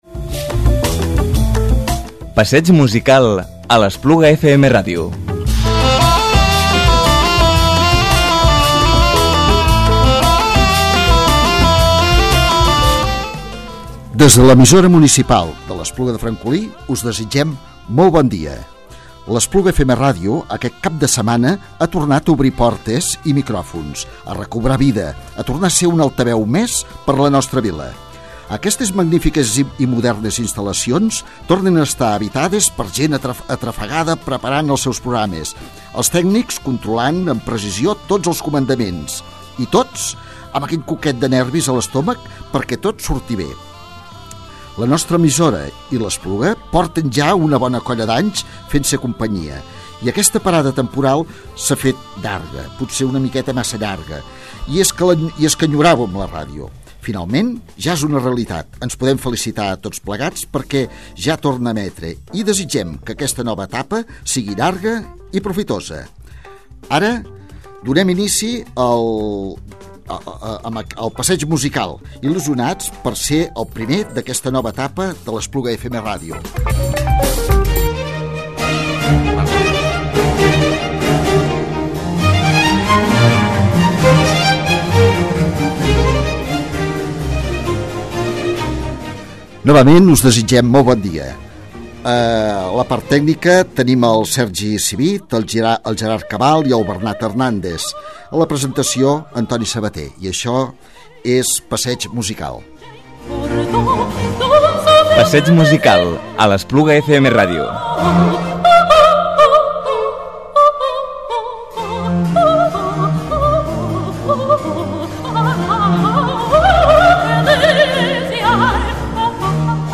i com a convidat l’alcalde de l’Espluga, David Rovira, qui fa un passeig musical per la seva vida, seleccionant les seves cançons preferides.